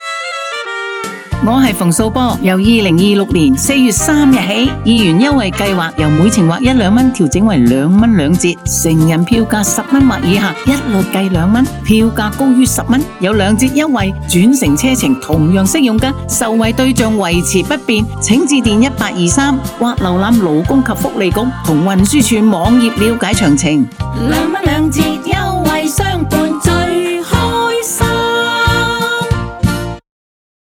电台广播